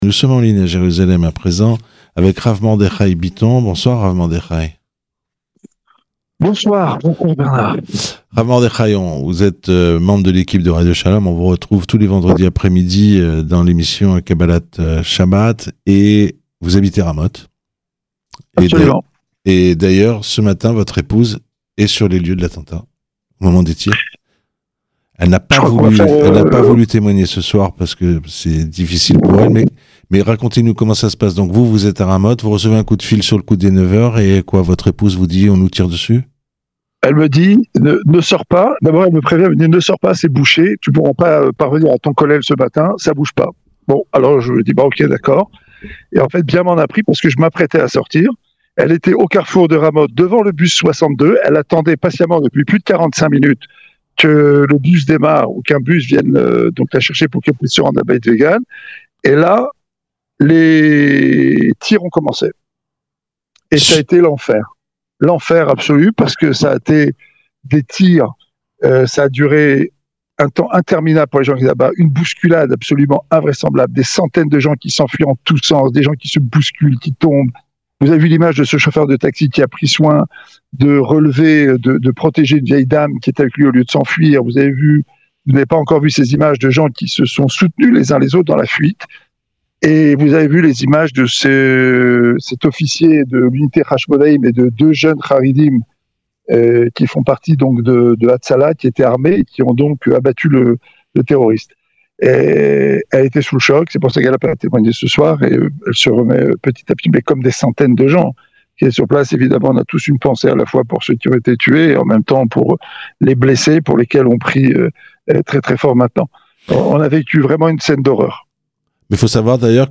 Attentat à Jerusalem : témoignage sur place, à Ramot